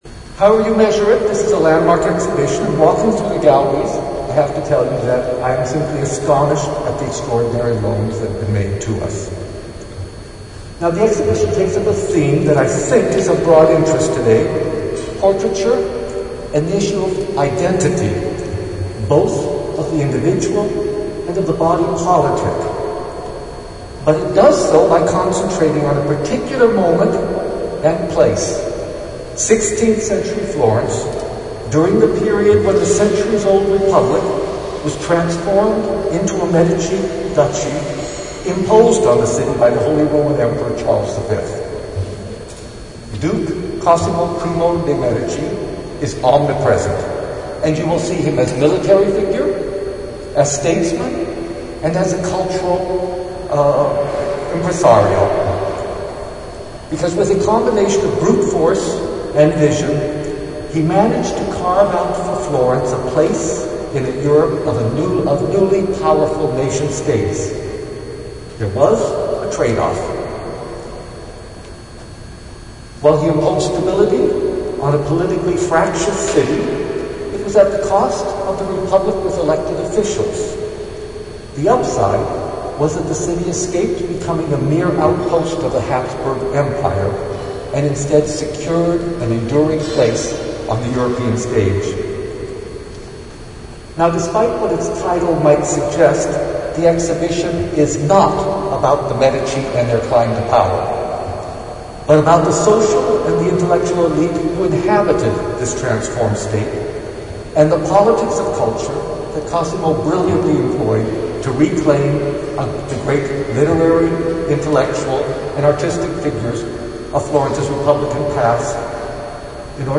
in a very echo-y room!